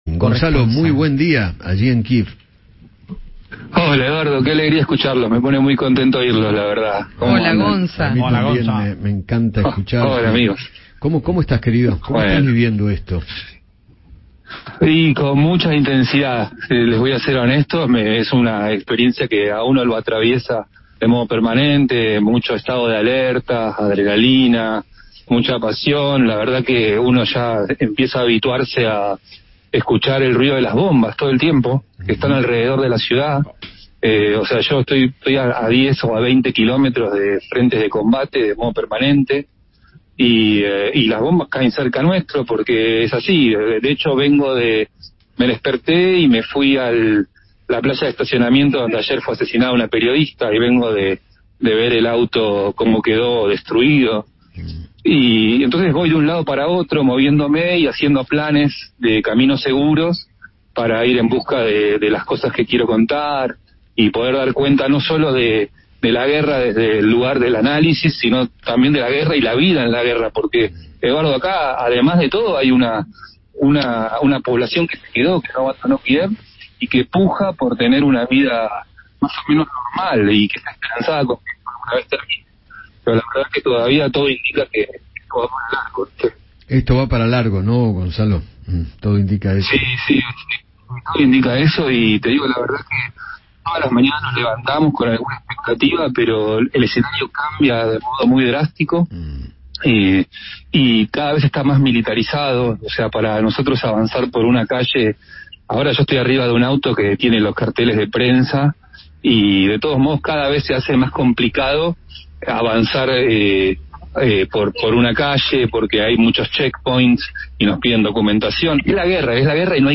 conversó con Eduardo Feinmann acerca de cómo se vive la guerra en las calles de Ucrania